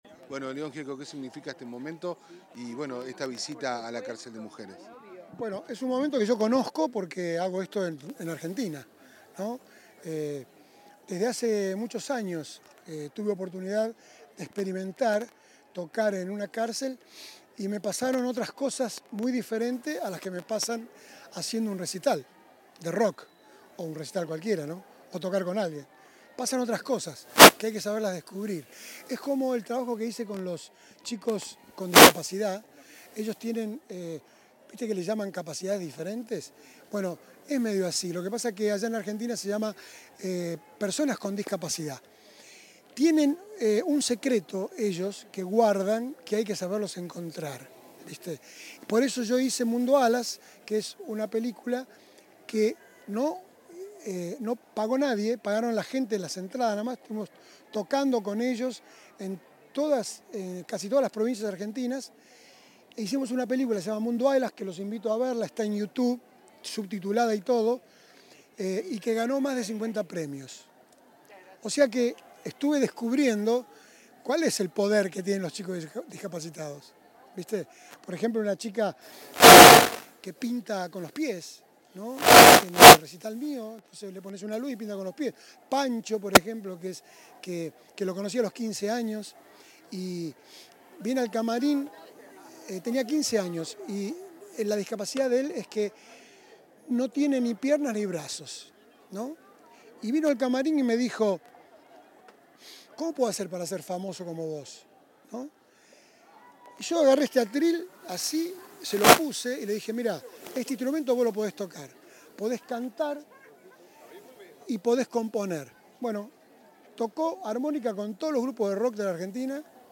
Declaraciones de León Gieco
Declaraciones de León Gieco 26/05/2025 Compartir Facebook X Copiar enlace WhatsApp LinkedIn El artista argentino León Gieco dialogó con la prensa en el marco de un espectáculo musical que brindó en la Unidad n.°5 del Instituto Nacional de Rehabilitación (INR).